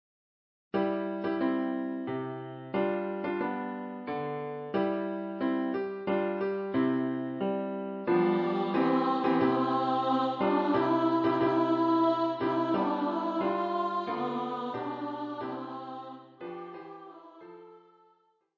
für Gesang, mittlere Stimme